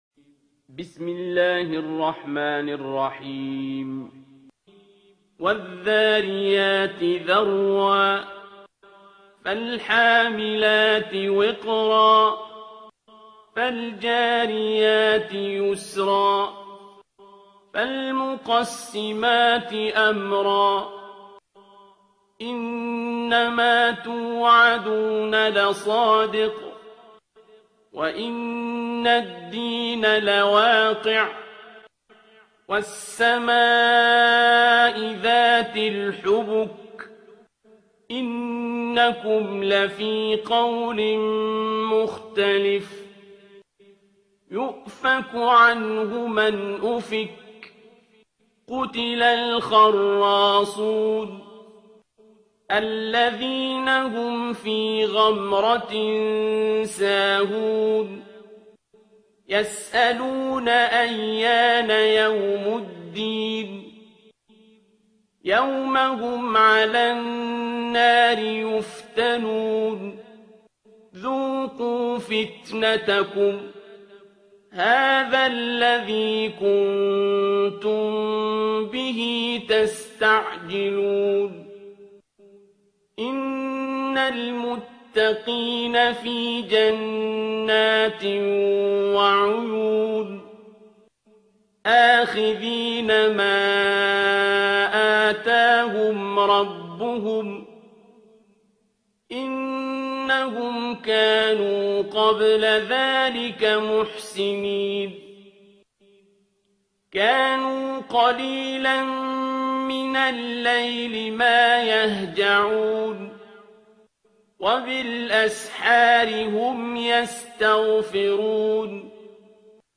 ترتیل سوره ذاریات با صدای عبدالباسط عبدالصمد